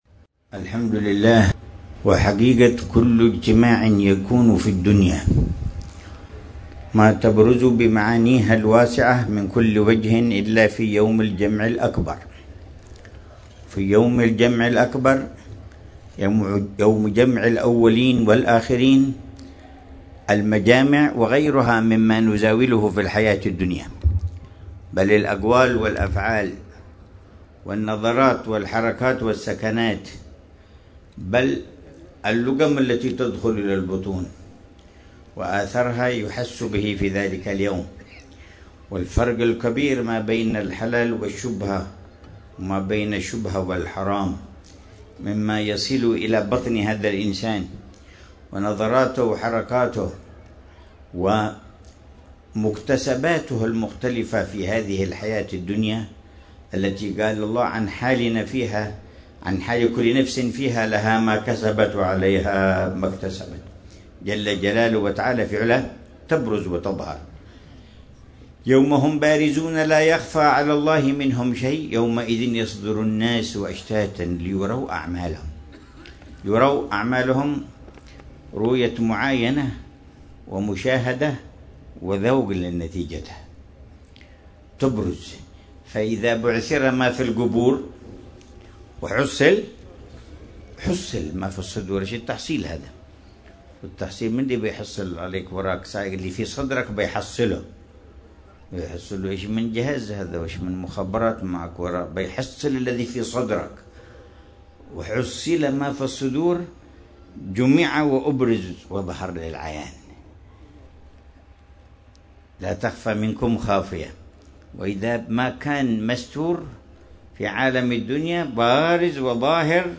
مذاكرة الحبيب عمر بن محمد بن حفيظ في حوش جمل الليل في منطقة صيف، بوادي دوعن، ليلة الإثنين 14 ربيع الثاني 1447هـ بعنوان: